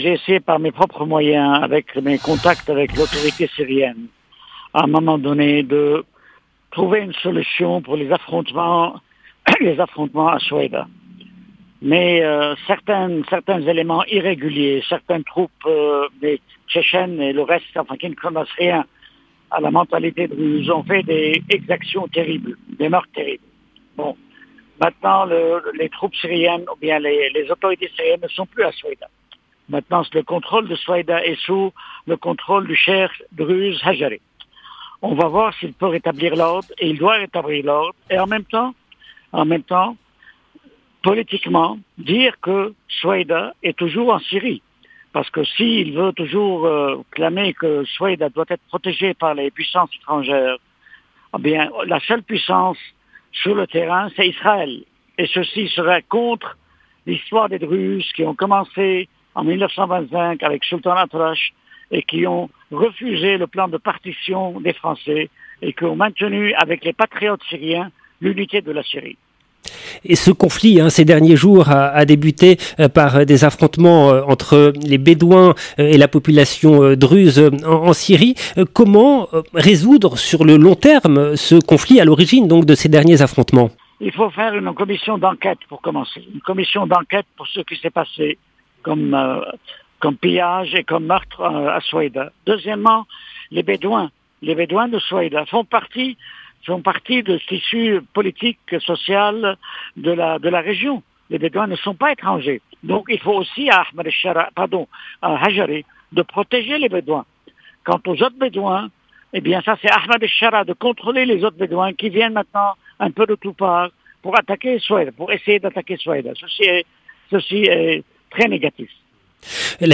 Entretien exclusif pour Radio Orient avec le dirigeant druze libanais Walid Joumblatt